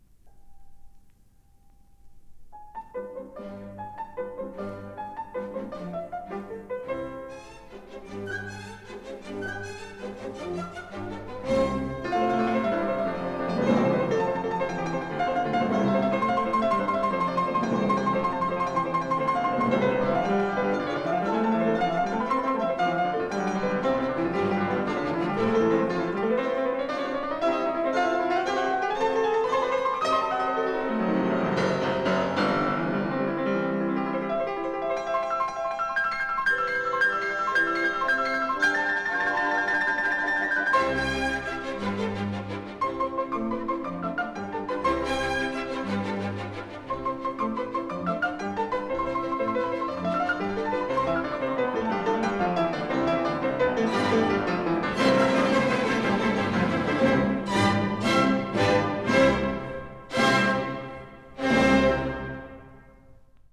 in C minor, Op. 37